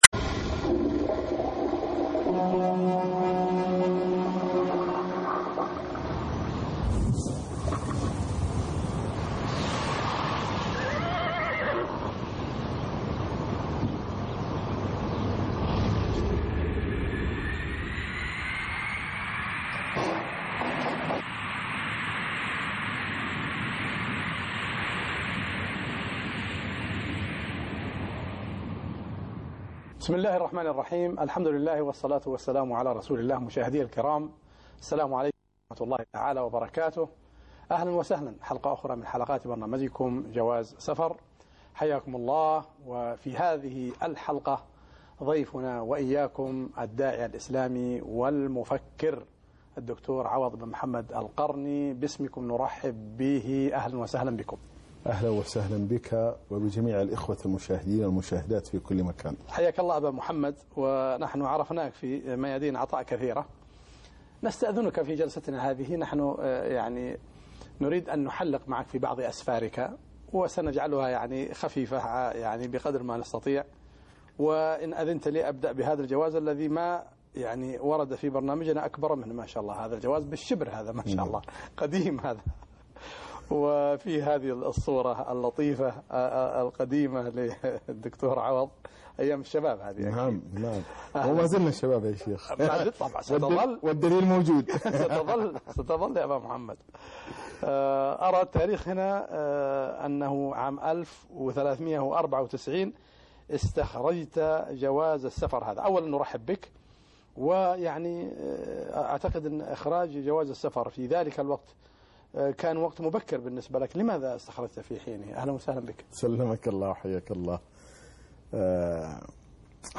لقاء مع الشيخ عوض القرني (5/8/2011) جواز سفر - قسم المنوعات